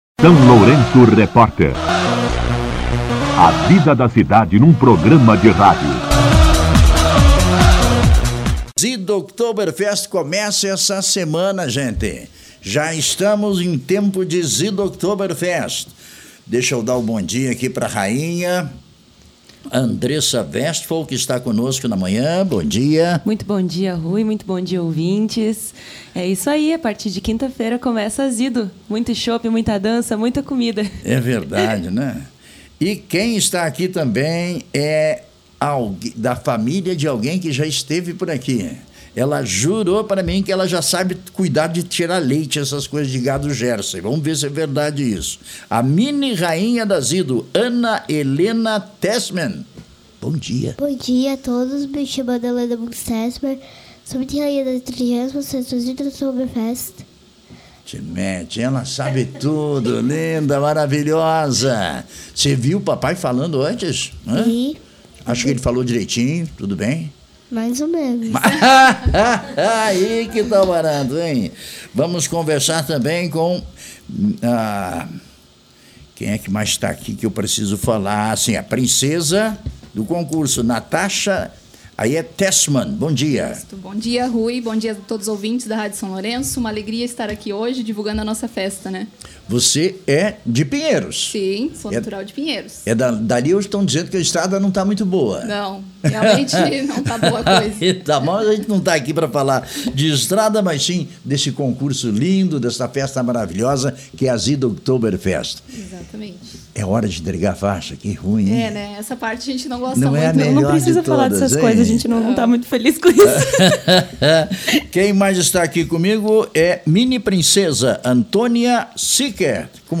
A Corte da 36ª Südoktoberfest esteve nesta segunda-feira no SLR RÁDIO para falar sobre a tradicional festa de São Lourenço do Sul, que começa nesta quinta-feira e chega repleta de novidades.